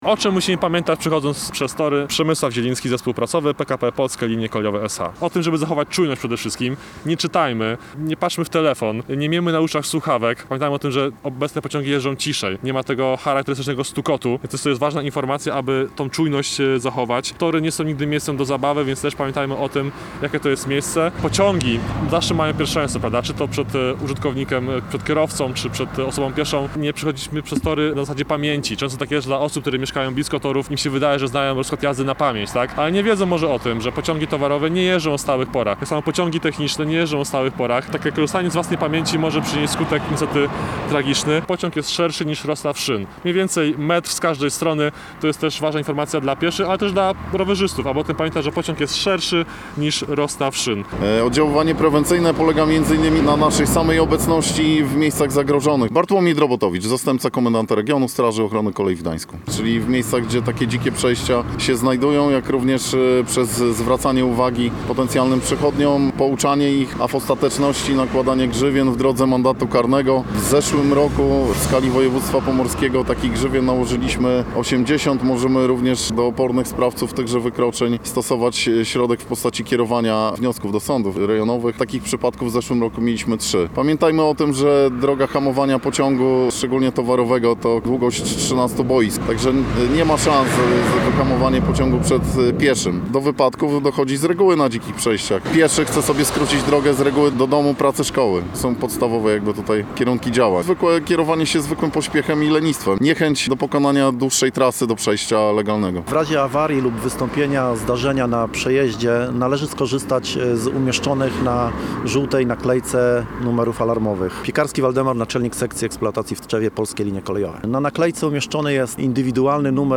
Posłuchaj materiału naszego reportera: https